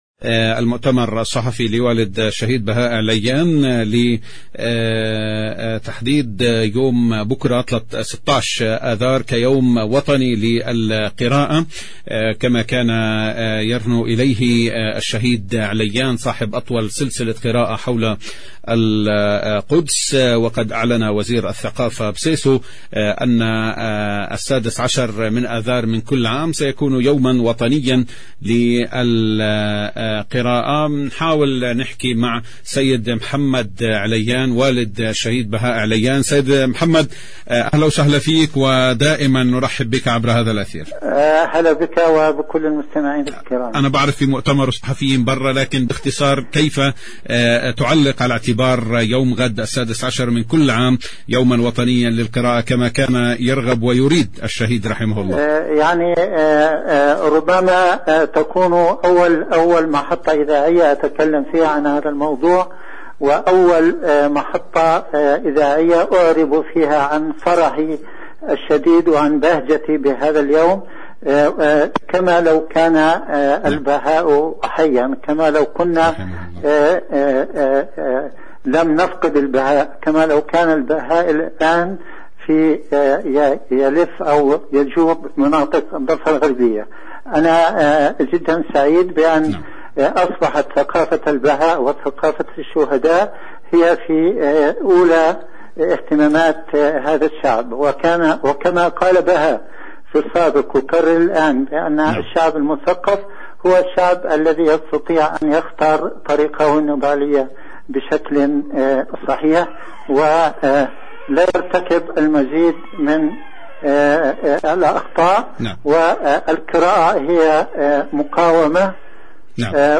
المقابلة بالصوت